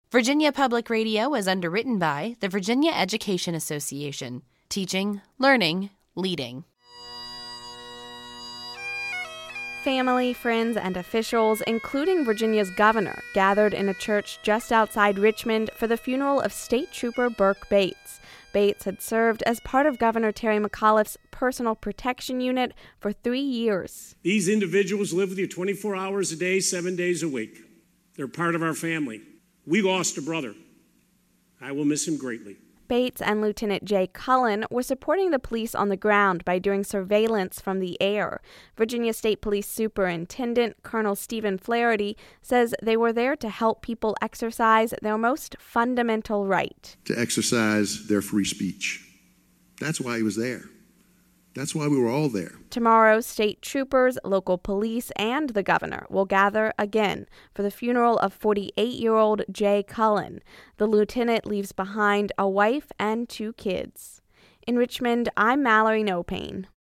vpr-trooper-funeral.mp3